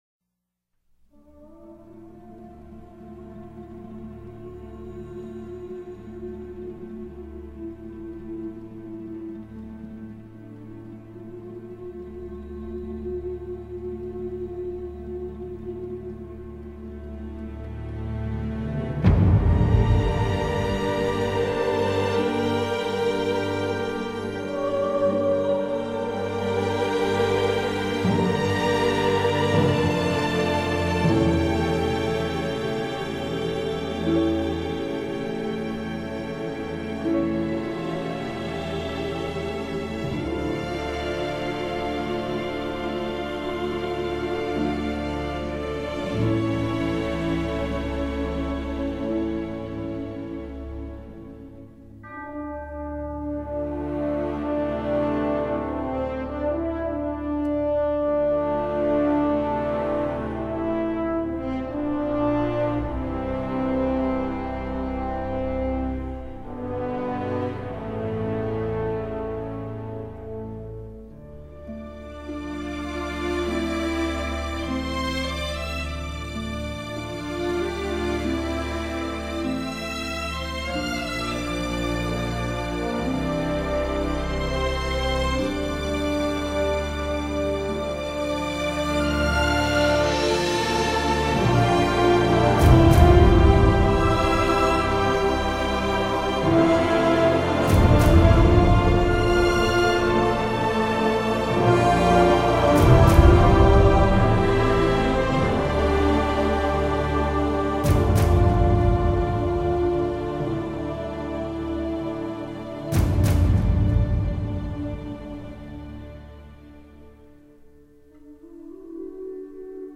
音乐诡异、壮观，恰如其分的与电影剧情吻合。